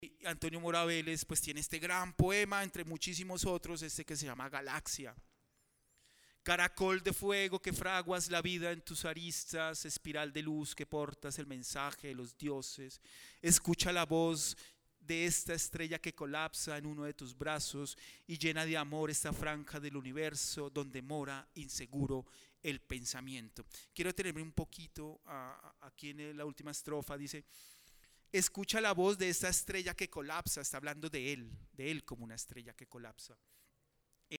conversaron acerca de la relación entre poesía y ciencia en la conferencia Estado Sólido: Poesía y Ciencia, en el segundo día de actividades de la Feria Internacional del libro de Guadalajara 2022.